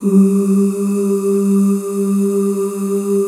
G2 FEM OOS.wav